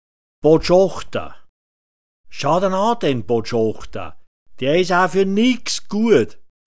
Botschochta [bo’dschochta] m